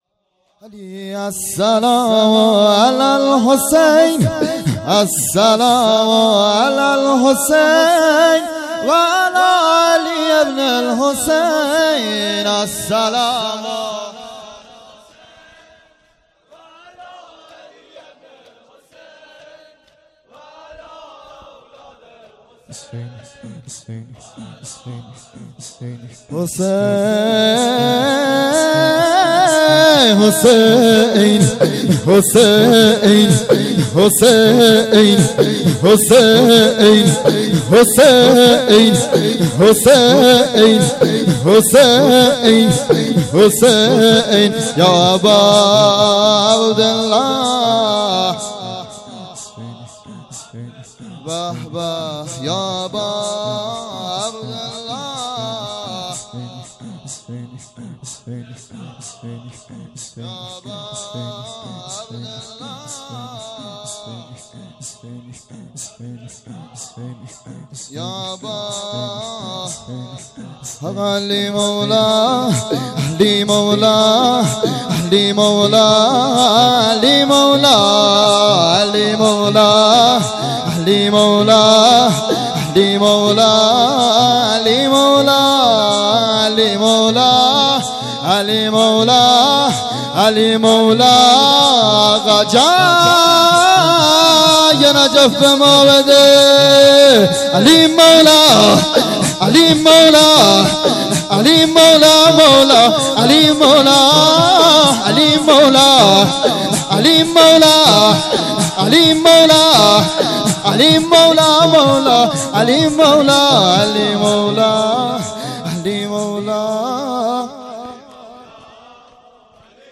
شور
مراسم احیای شب های قدر شب ۱۸ رمضان شهادت امیرالمومنین علیه السلام ۱۴۰۳